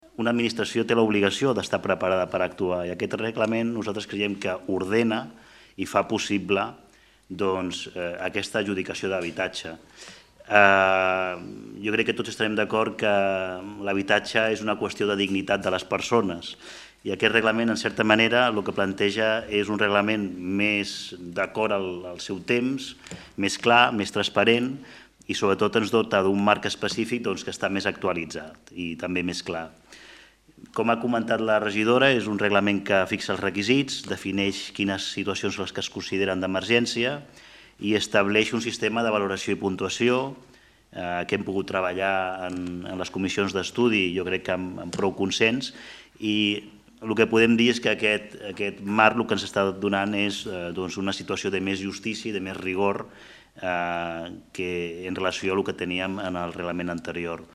Javier González, regidor Transició Digital i Sostenible